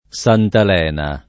santalena [ S antal $ na ] → santelena